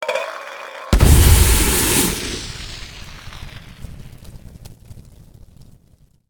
smokegrenade.ogg